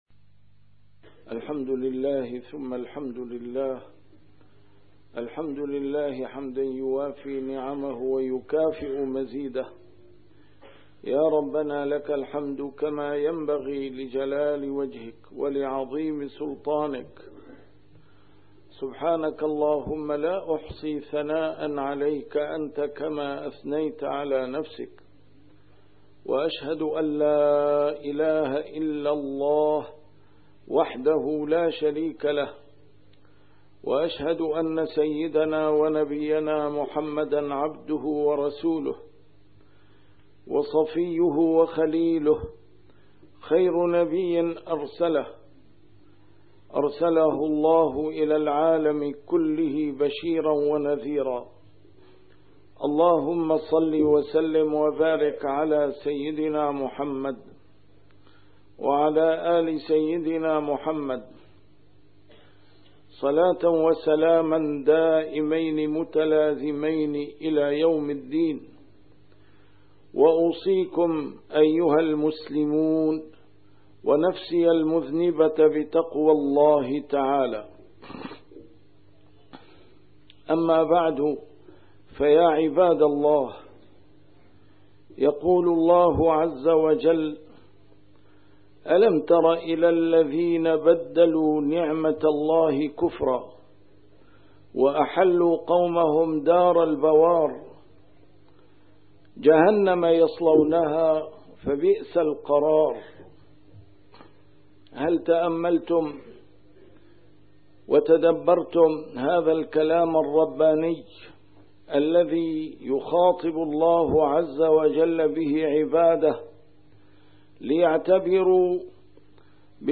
A MARTYR SCHOLAR: IMAM MUHAMMAD SAEED RAMADAN AL-BOUTI - الخطب - ألم تر إلى الذين بدلوا نعمت الله كفراً